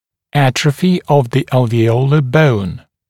[‘ætrəfɪ əv ðə ˌælvɪ’əulə bəun][‘этрэфи ов зэ ˌэлви’оулэ боун]атрофия альвеолярноой кости